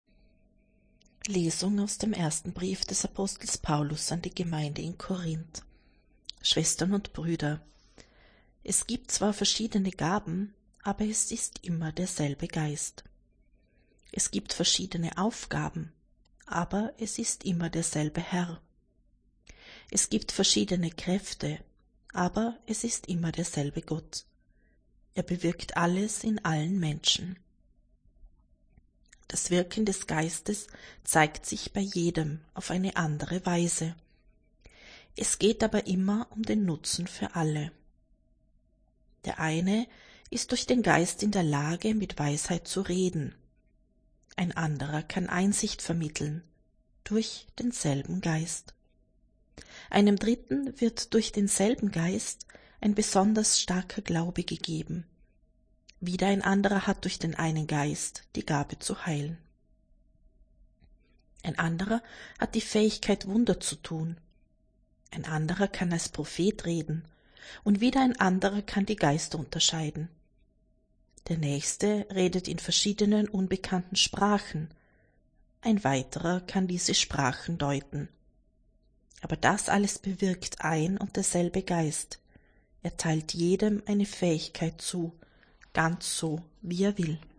Wenn Sie den Text der 2. Lesung aus dem Brief des Apostels Paulus an die Gemeinde in Korínth anhören möchten:
Wir wollen einen Versuch starten und werden ab dem Beginn des neuen Lesejahres die Texte in der Länge der biblischen Verfasser lesen.